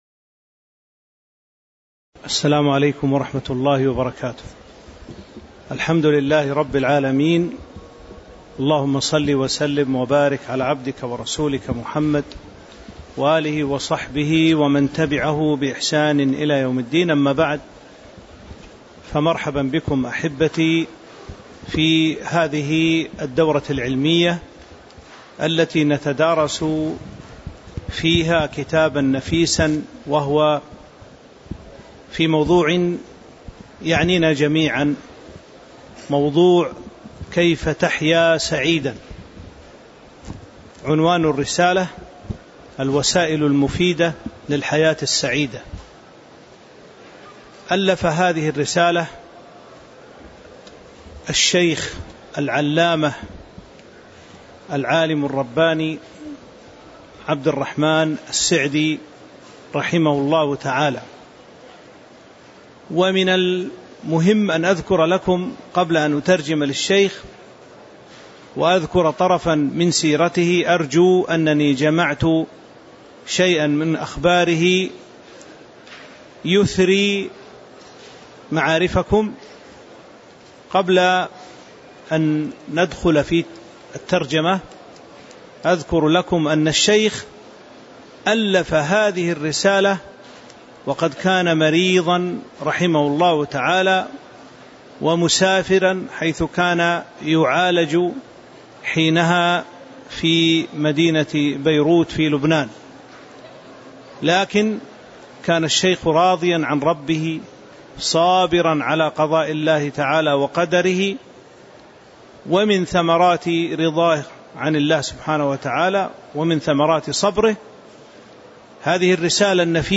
تاريخ النشر ١٤ ربيع الثاني ١٤٤٥ هـ المكان: المسجد النبوي الشيخ